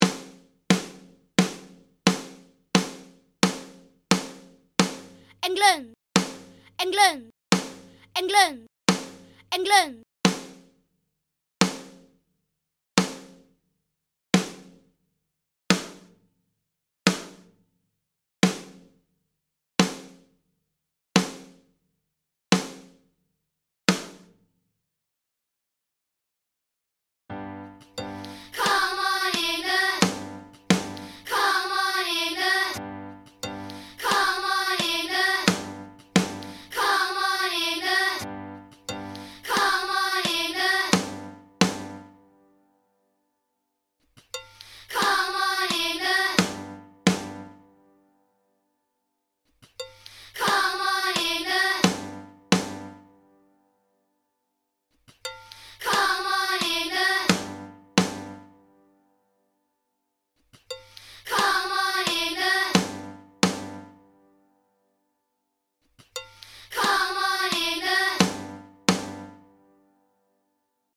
5) The guide track plays 8 snare hits for the timing, then four times with the demo vocals for the first chant ‘England’. After this it plays without the vocals and this is when the kids need to do their versions. It then moves on to ‘Come On England’ and does the same thing but leaves every other guide vocal in so the kids can keep it in tune (ish!).
6) There are gaps in the guide track for them so you can leave it on when recording.
7) It will be helpful to visually count the beat as some of the guide clicks/notes are removed so they don’t sound over the start or end of the kids’ vocals.
SOFE-CHANT-GUIDE_2.mp3